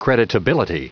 Prononciation du mot creditability en anglais (fichier audio)
Prononciation du mot : creditability